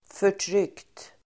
Uttal: [för_tr'yk:t]